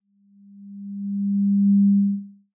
b. Beeeeeeep
Dit object is een oscillator die een sinus-toon genereert.
Zet de frequentie op 200 Hz en schuif voorzichtig het volume omhoog om de sinustoon te horen.